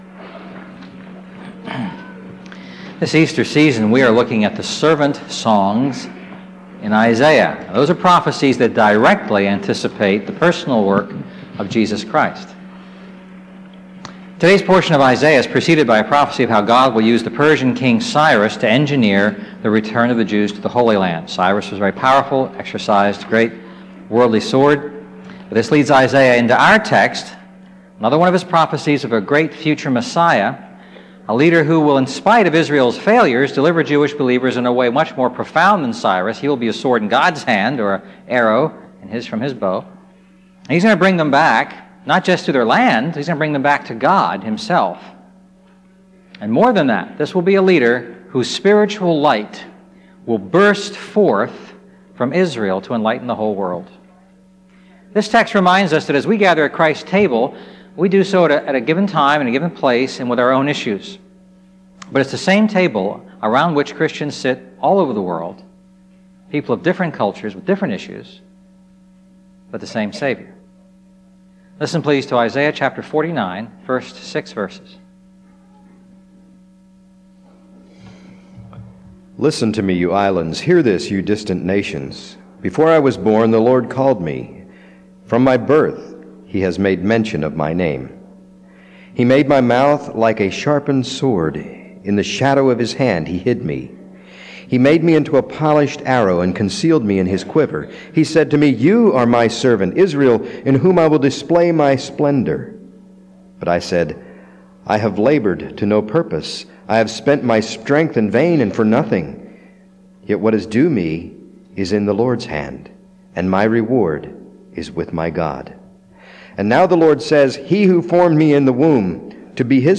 A message from the series "The Lord Saves."